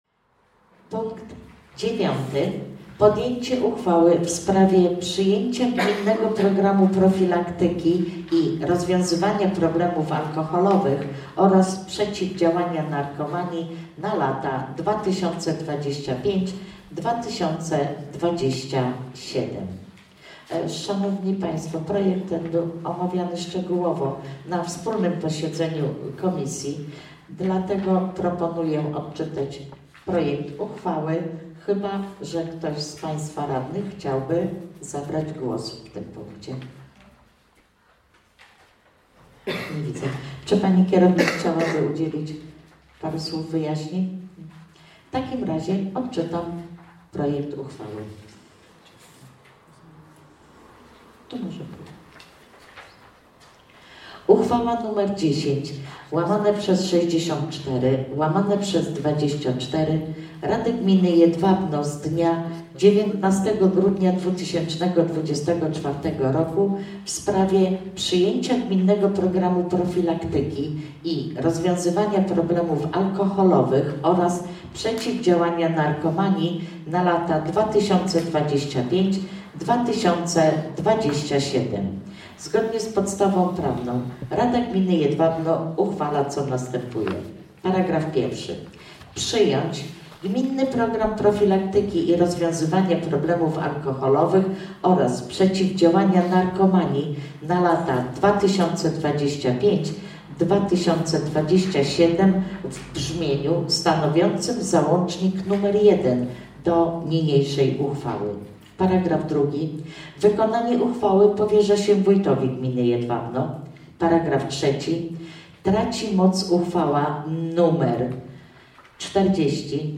Nagrania audio z sesji Rady Gminy Jedwabno kadencja IX 2024-2029